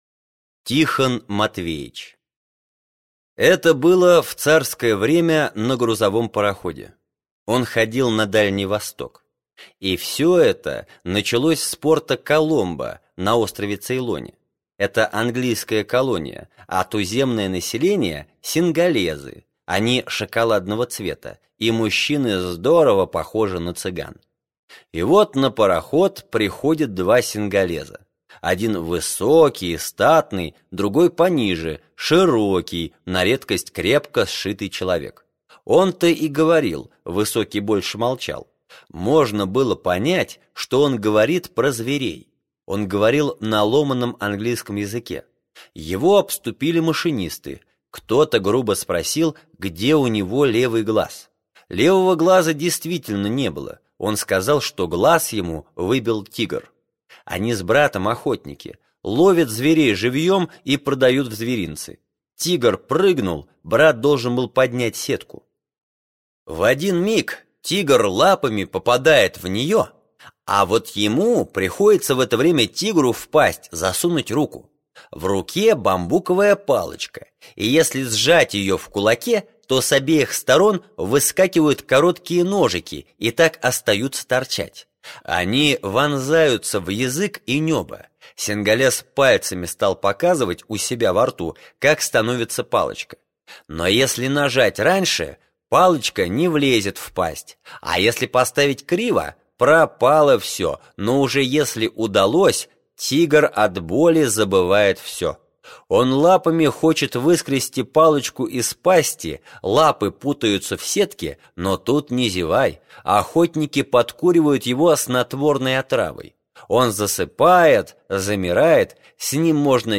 Тихон Матвеич - аудио рассказ Житкова - слушать онлайн